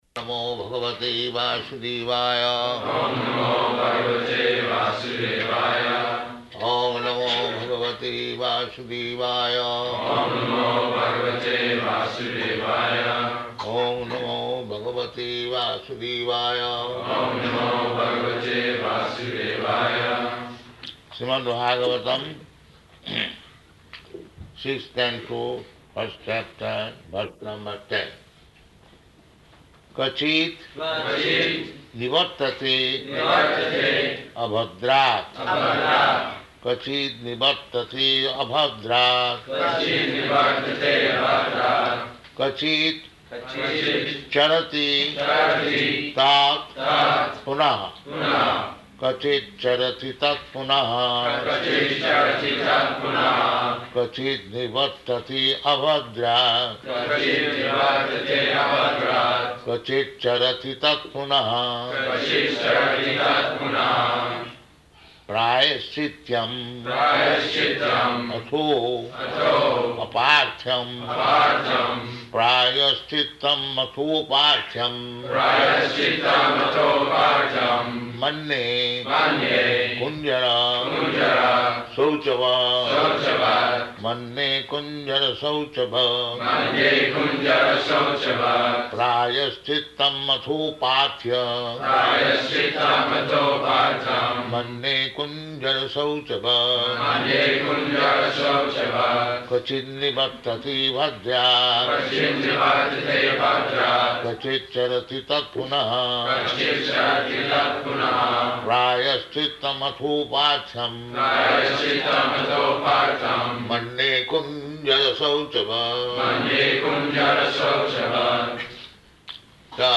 Śrīmad-Bhāgavatam 6.1.10 --:-- --:-- Type: Srimad-Bhagavatam Dated: May 11th 1976 Location: Honolulu Audio file: 760511SB.HON.mp3 Prabhupāda: Oṁ namo bhagavate vāsudevāya.
[leads devotees in chanting]